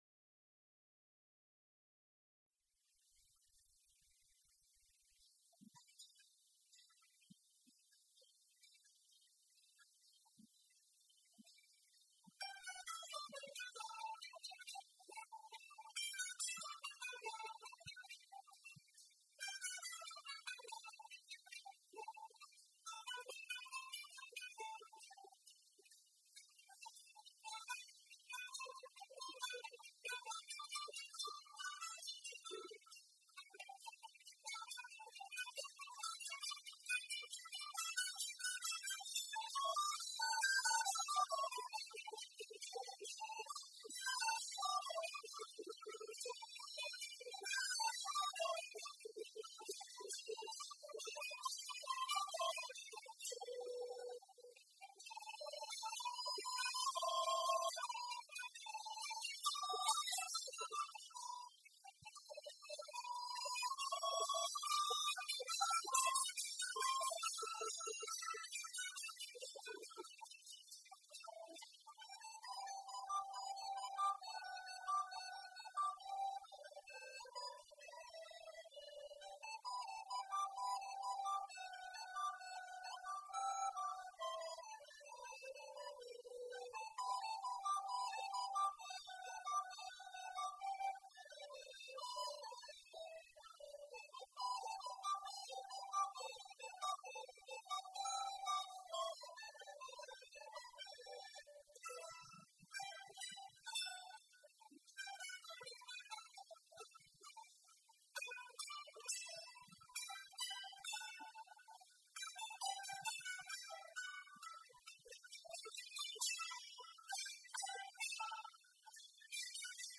Two for Texas, pt. 2 (live from CMSA Santa Rosa, November 17, 2018)
Last month I posted an audio recording of a performance from the Saturday Open Mic session of the 2018 Classical Mandolin Society of America convention in Santa Rosa, CA. This month we have video evidence featuring a chamber group of plucked string instruments (including me in a ball cap playing a very fine Collings MT-O mandolin), augmented by a trio of recorders, from the same lunchtime session.
I had a lot of fun adding a "horn section" to the plucked string ensemble sound.
The enthusiastic applause at the end was very gratifying and is indicative of the spirit that prevails throughout the CMSA conventions, especially during the Open Mic sessions.